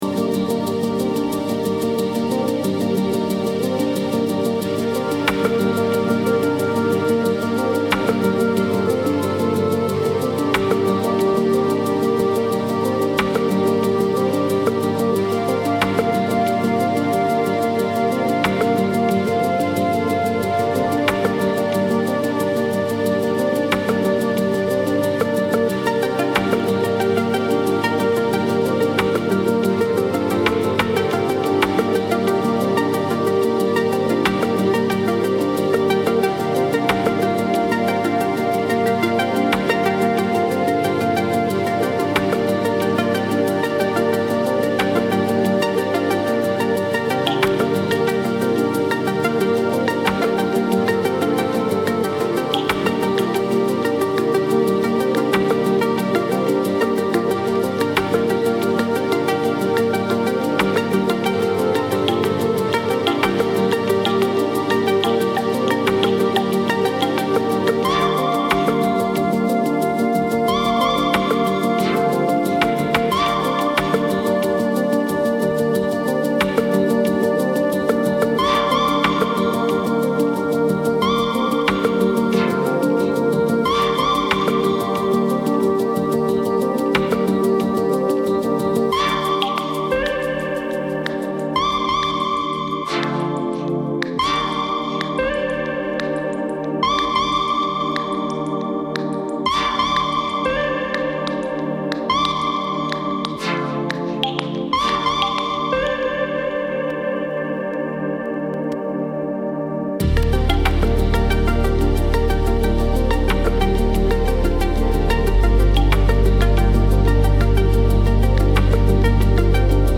奥深く味わいのある選曲、それぞれの楽曲の魅力を引き立てるスムースかつ確かなミックススキルにも唸らされる、
ダイジェストになります。